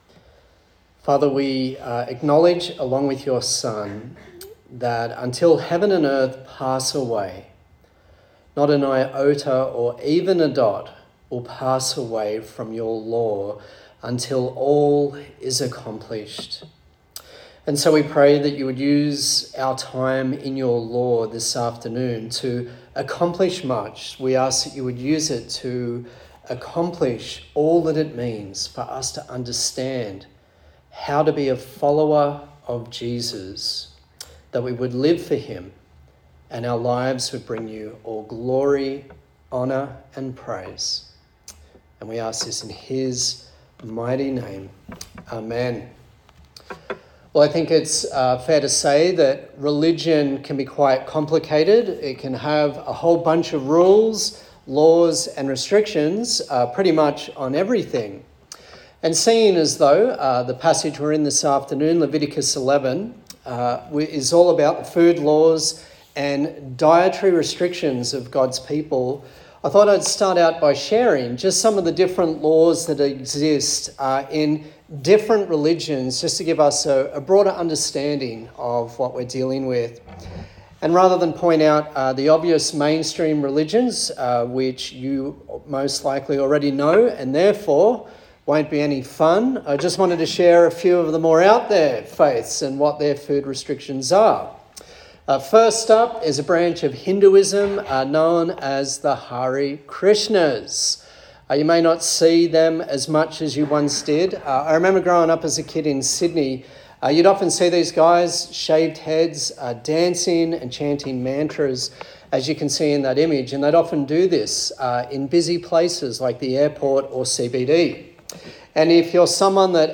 A sermon in the series on Leviticus
Service Type: Sunday Service